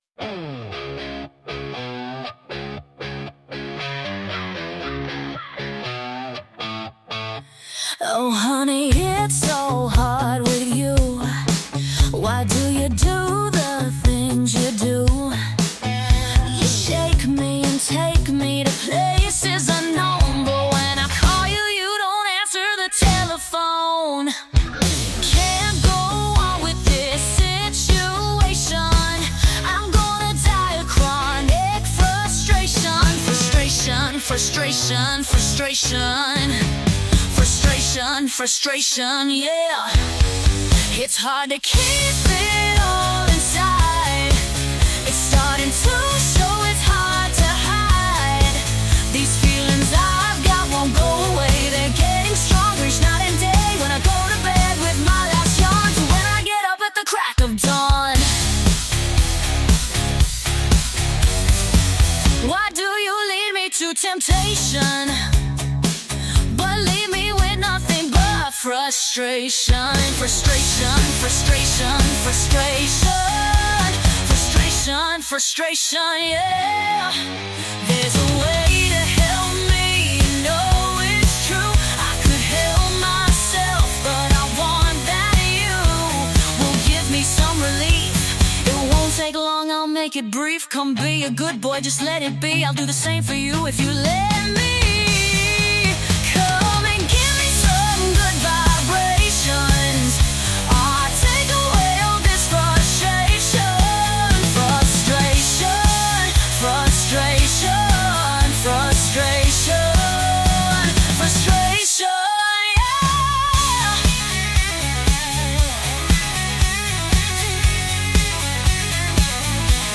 an emotional and passionate song